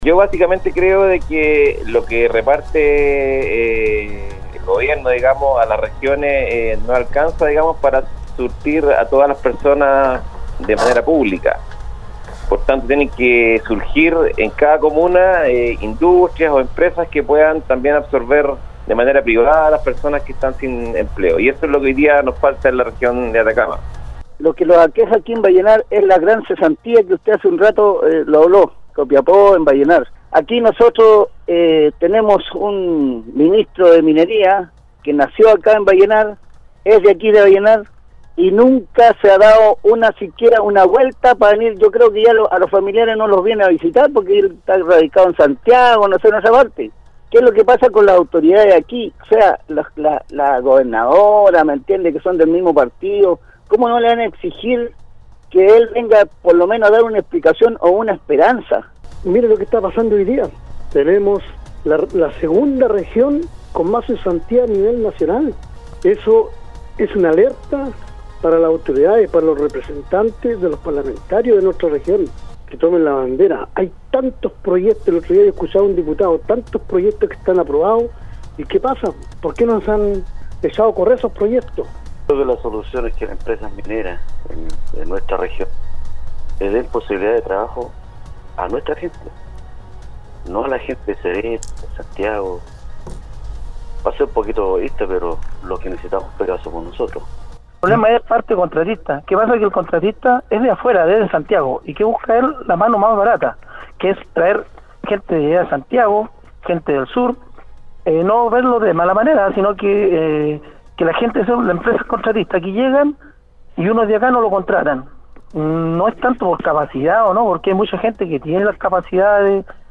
La mañana de este martes, los auditores de Nostálgica participaron de foro del programa Al Día, donde se refirieron a las negativas cifras entregadas por el INE Atacama, que posicionan a la región en el segundo lugar del Desempleo a nivel nacional.
Pese a que las autoridades locales destacaron la creación de empleo y los esfuerzos que se llevan a cabo por generar las condiciones para que las personas encuentren una fuente laboral, la percepción de las personas que llamaron al programa es distinta, apuntando al sector público y privado en esta coyuntura de magras cifras para Atacama.